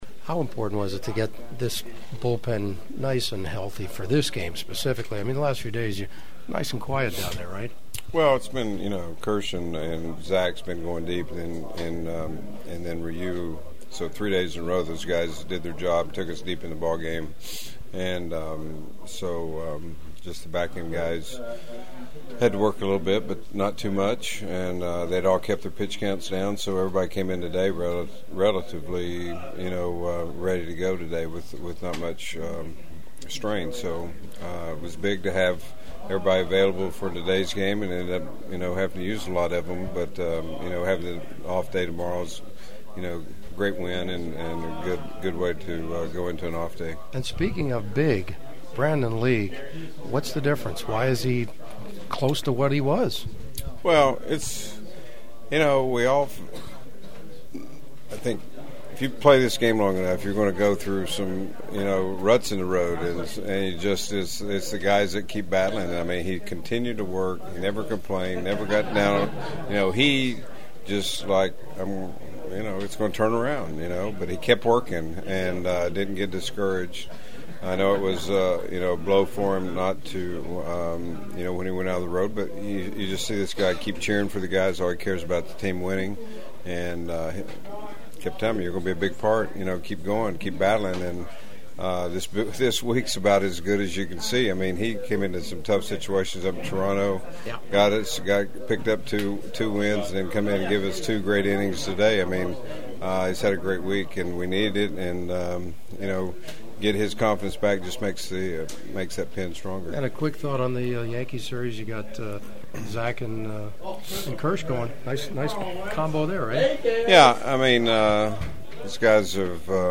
The following are my postgame clubhouse interview including some preview thoughts on the Yankees invading Dodger Stadium for 2 nights starting on Tuesday.
Dodgers pitching coach Rick Honeycutt on his guys’ strong outing and how this sets up the Yankees series: